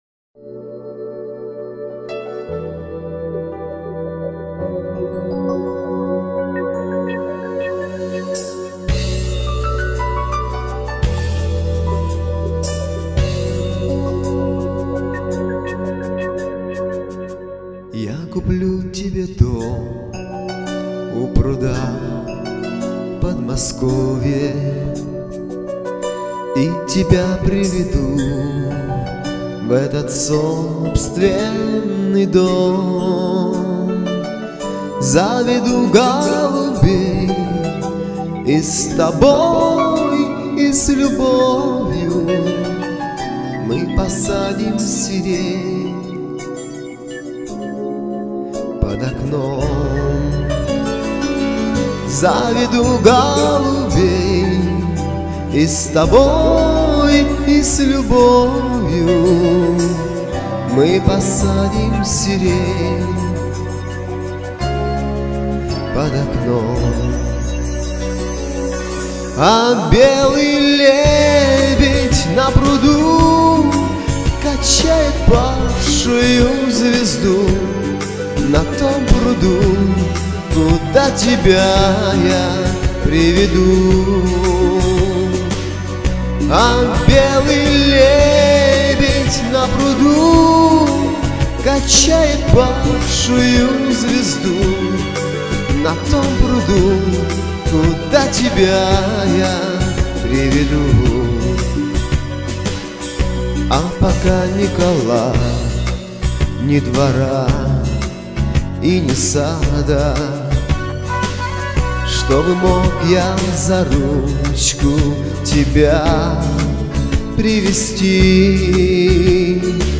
чуть подальше микрофон - "пыканья" в него стучат....